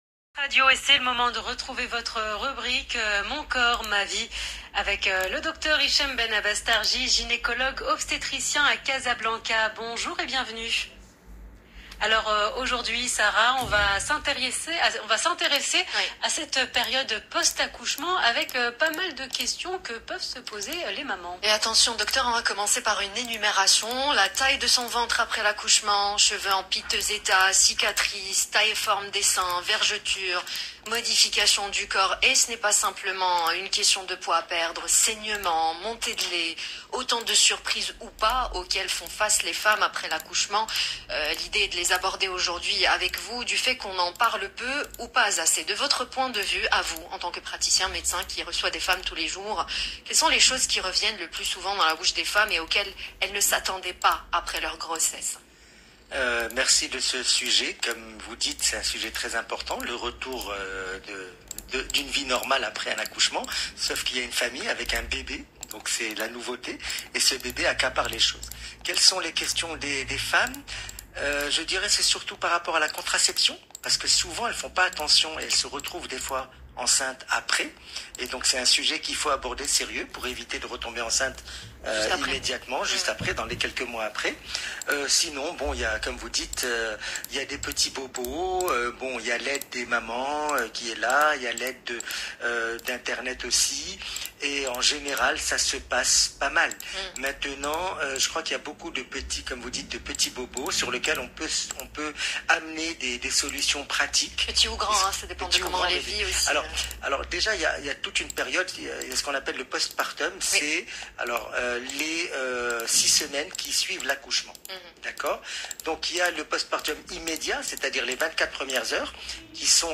Plus d’explications dans cette interview de l’Heure Essentielle sur Luxe Radio du 15 mars 2022